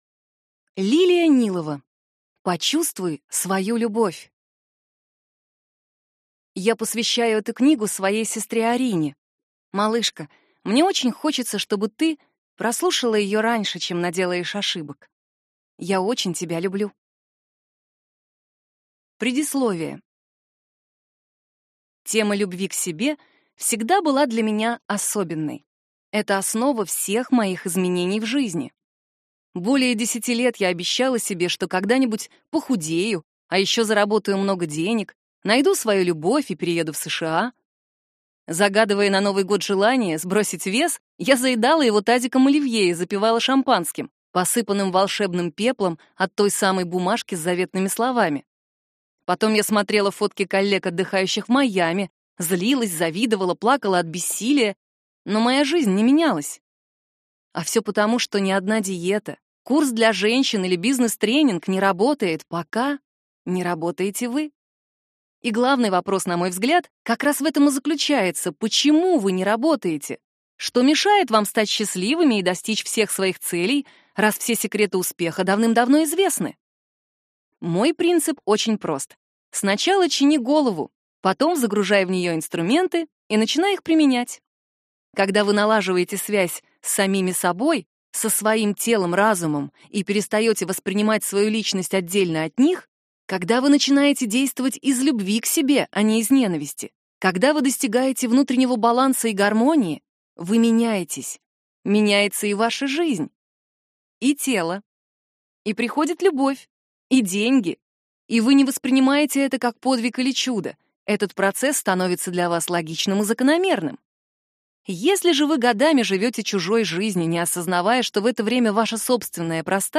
Аудиокнига Почувствуй свою любовь | Библиотека аудиокниг